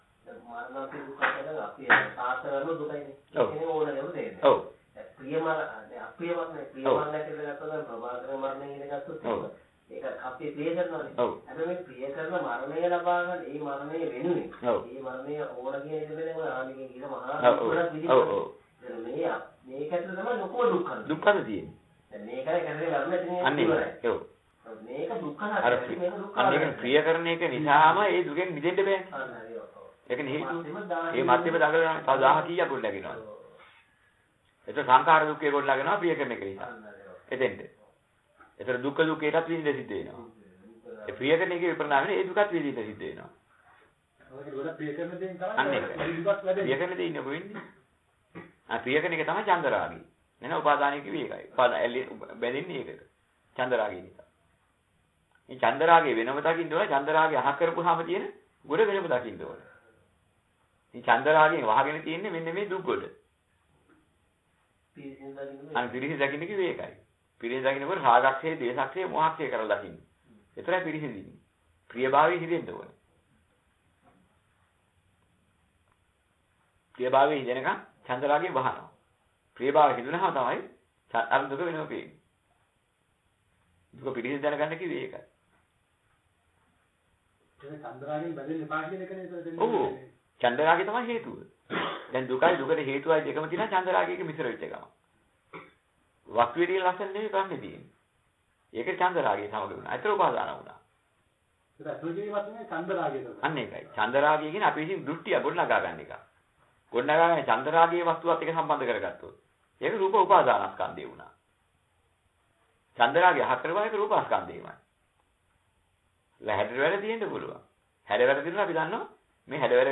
දේශනාවට සවන් දෙන්න (අහන ගමන් කියවන්න)